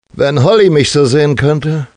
In the German version, actor Manfred Lehmann - well known as the voice of Bruce Willis in the movies - was given the task of synchronising the main character.